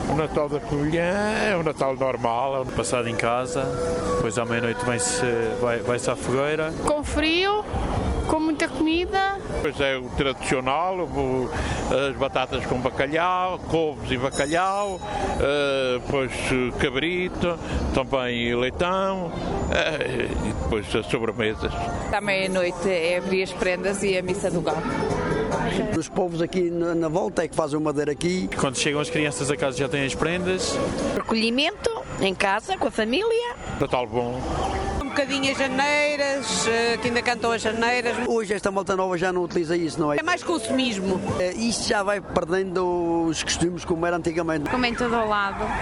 vox_pop_natal.mp3